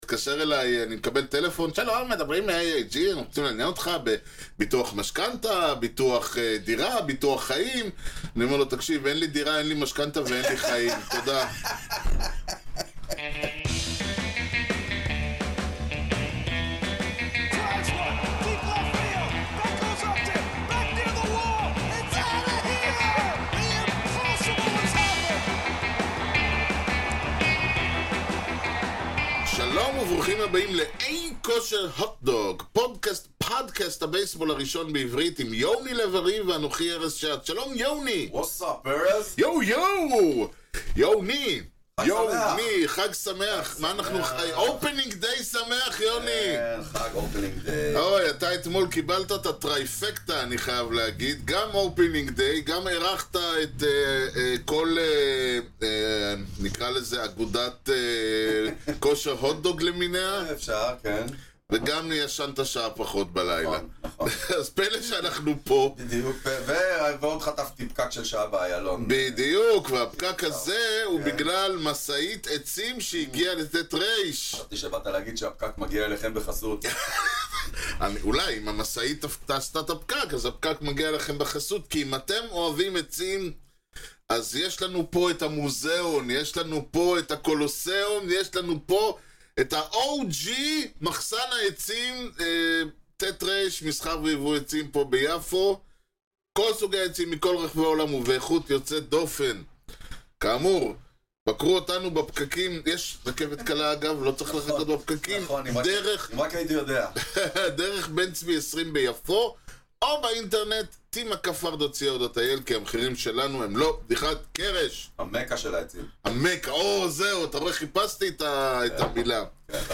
ולכך כינסנו אותם (ואת עצמנו) לשיחה על העונה שעברה וזו שתהיה, על השמות המרתקים שיעלו ויבואו בנאשיונל ליג סנטרל ובכלל, פנטזי בייסבול, עונות פיננסיות, פיצ׳רים מהמיינור ליג, ועל אוליביה דאן. וגם חדנו את חידת השבוע והרכב השבוע.